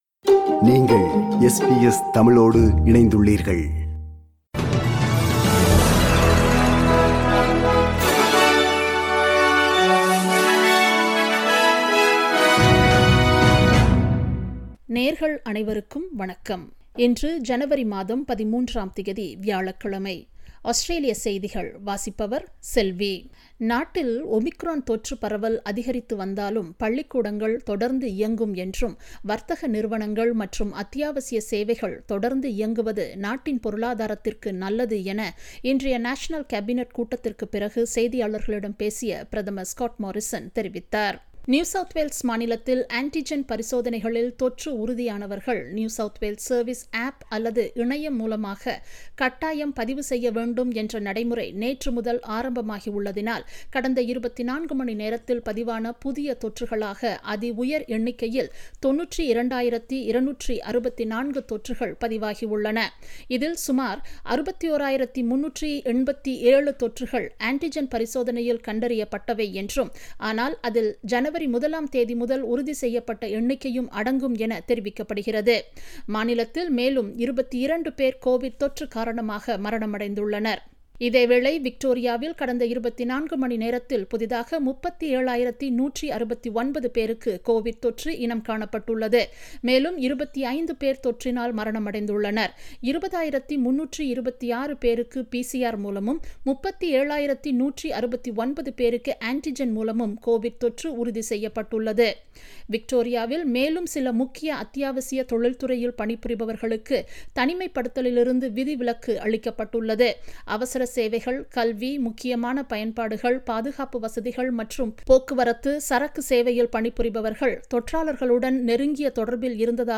Australian news bulletin for Thursday 13 January 2022.